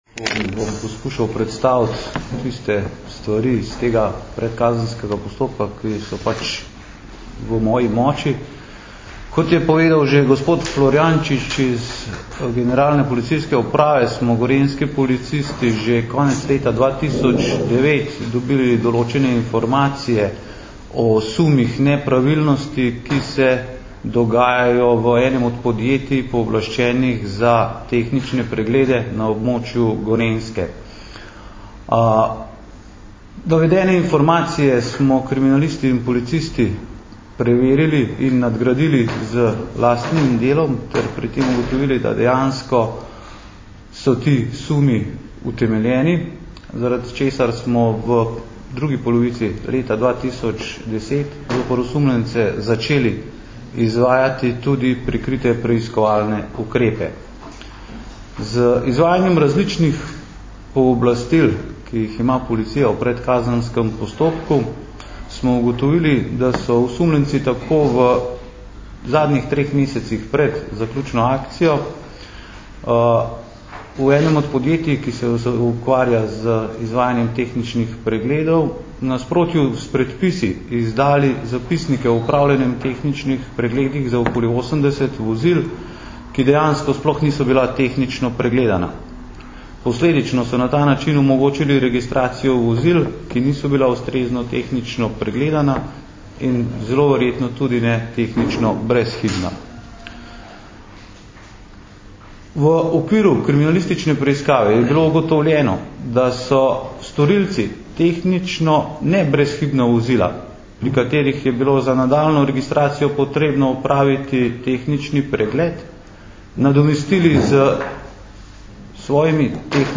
Na današnji novinarski konferenci v Kranju smo predstavniki Generalne policijske uprave in Policijske uprave Kranj predstavili rezultate uspešno zaključene kriminalistične preiskave sumov korupcije pri registracijah vozil na območju Lesc.